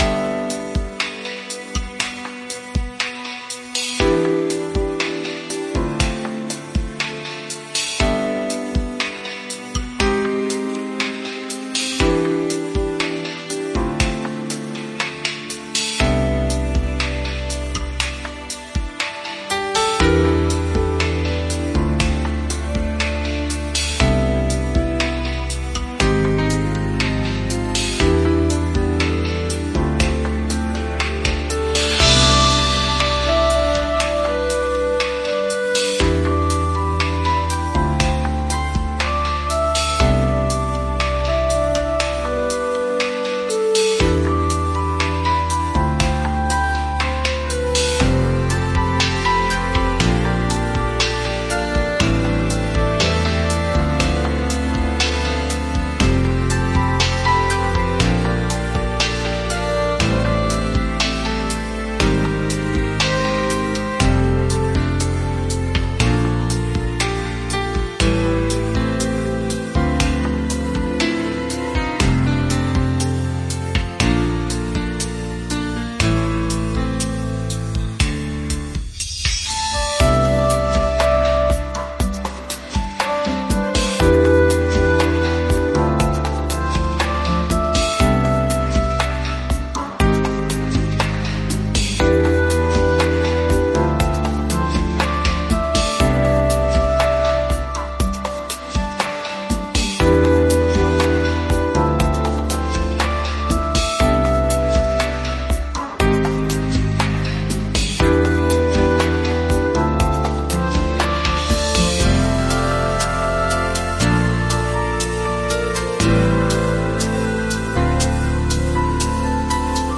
・メインセクションは、ピアノとサックスが中心となり、スムースなビートが曲の進行を支えます。